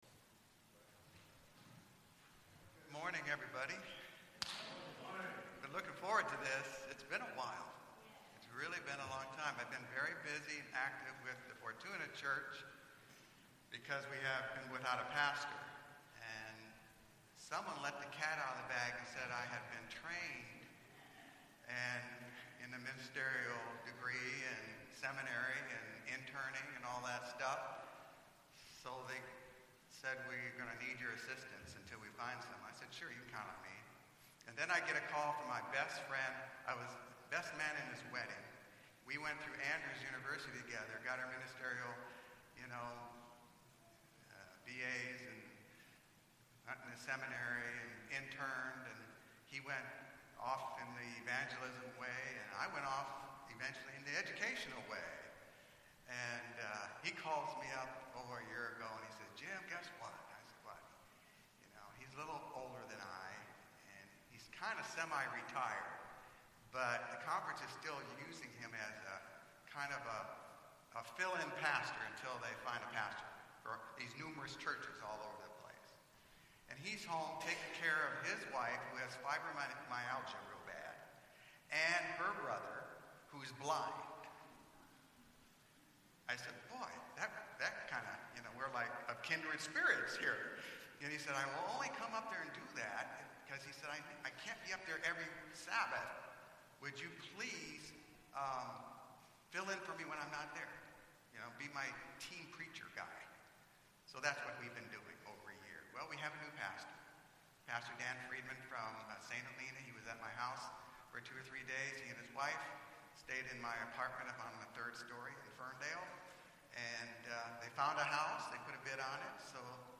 Sermons
Arcata-McKinleyville Seventh-day Adventist Church McKinleyville, California